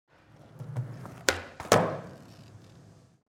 دانلود آهنگ تصادف 54 از افکت صوتی حمل و نقل
دانلود صدای تصادف 54 از ساعد نیوز با لینک مستقیم و کیفیت بالا
جلوه های صوتی